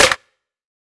BULLSHIT WANNABE EDM TRAP SNARE PERC THING IDFK .wav